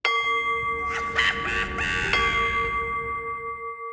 cuckoo-clock-02.wav